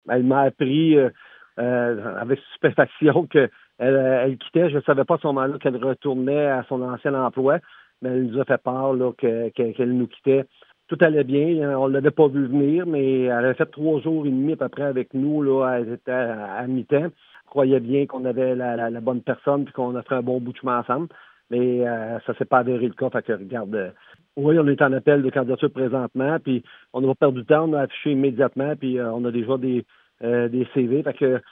Le maire de Sainte-Cécile-de-Milton, Paul Sarrazin, a commenté le dossier